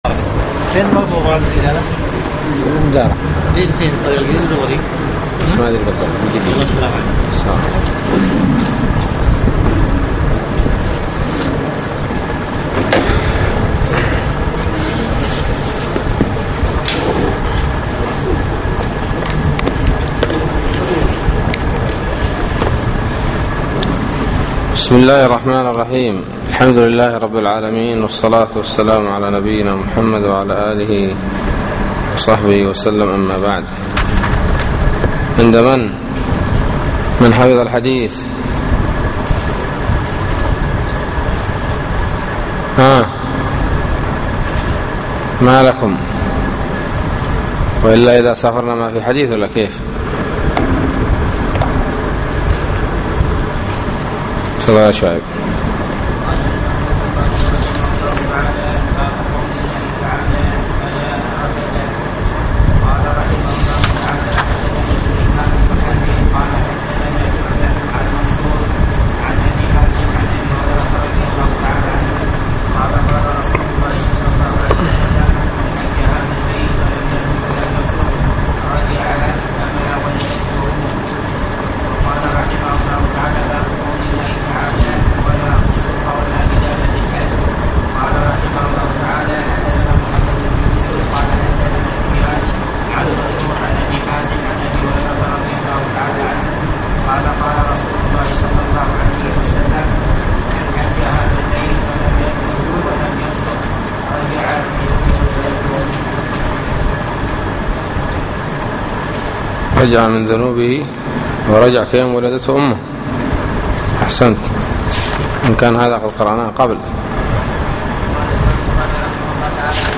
الدرس الثاني: باب اذا راى المحرمون صيدا فضحكوا ففطن الحلال وباب لا يعين المحرم الحلال في قتل الصيد